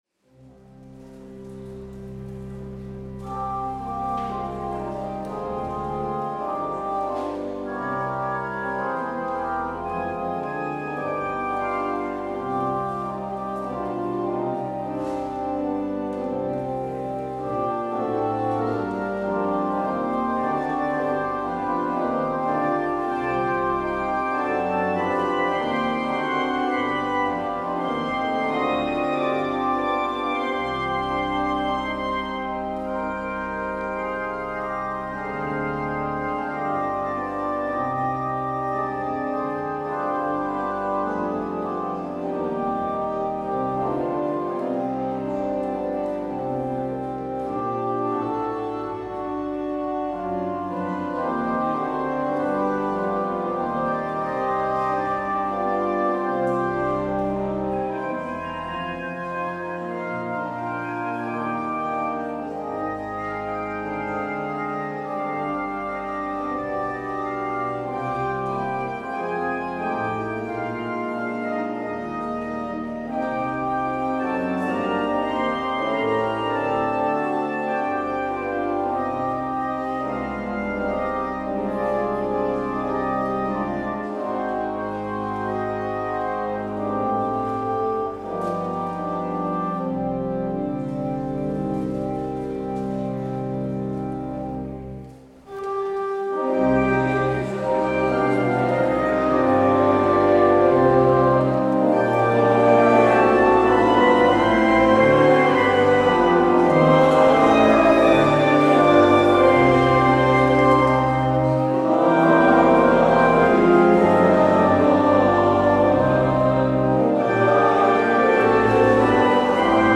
Luister deze kerkdienst terug